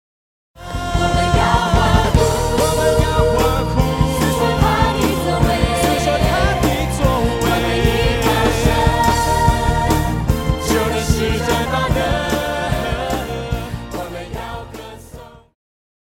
Christian
Vocal - female,Vocal - male
Band
POP,Christian Music
Instrumental
Voice with accompaniment